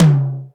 ROCK TOM MID.wav